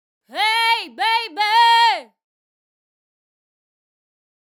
Its character is often direct, loud, and shouted, like when you call ‘hey’ after someone in the street.
Vowels in Overdrive
Hence, ‘EE’, ‘I’, and ‘A’ are altered to ‘EH’.
#110 (Female)